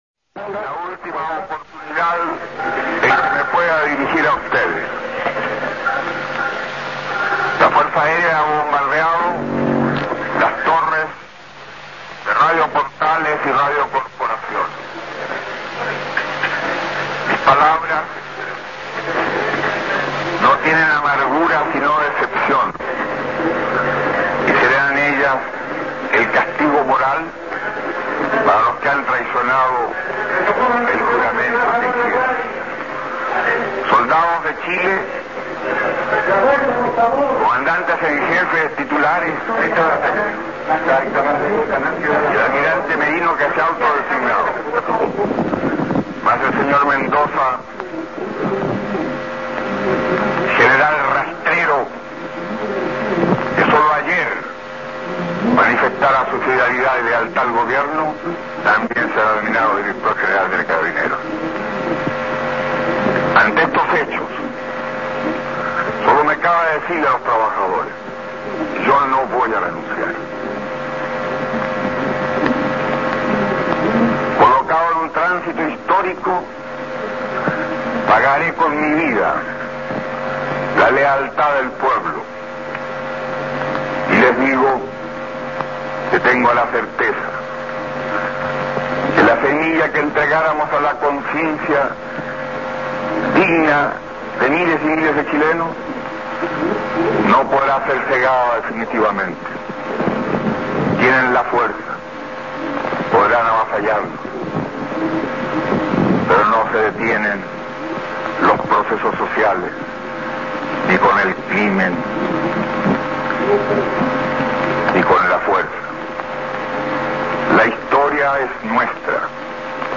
Allende, con tono calmado y firme a pesar de las circunstancias, pronuncia a las 10:15 por Radio Magallanes su último y lúcido discurso a la Nación.